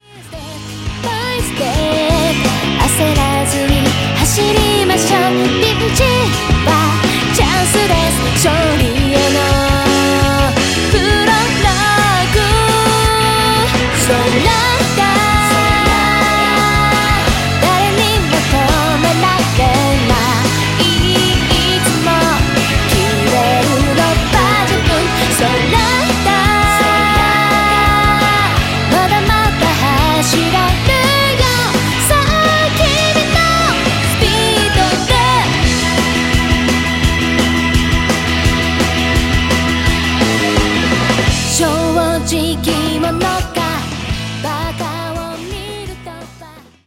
歌詞に込められたメッセージと飾らない真っ直ぐで優しくも力強い歌声。
そして、フルートの音色が心地よく心に響き、極彩色の音と言葉が、夢と勇気と希望を与えてくれる一枚！